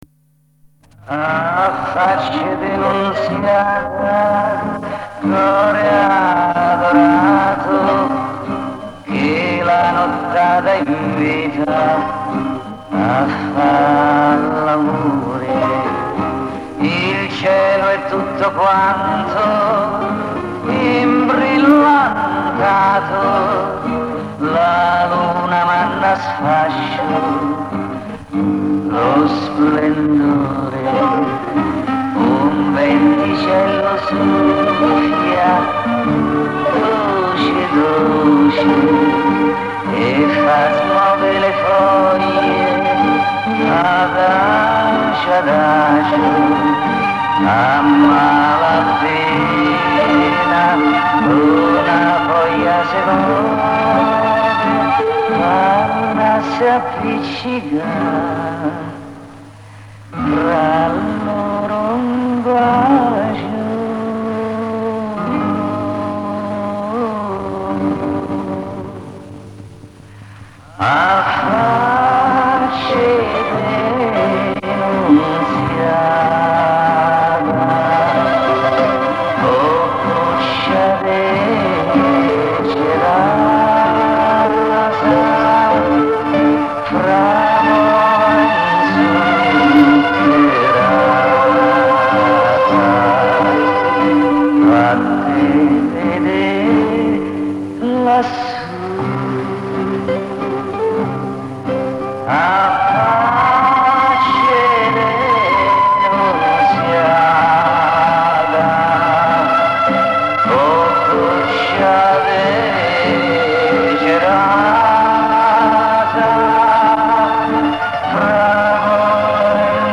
Прикреплённый файл - это песня 60 или начала 70-х годов. Судя по тембру голоса, похоже на Nicola di Bari Помогите, пожалуйста, определить исполнителя и название песни.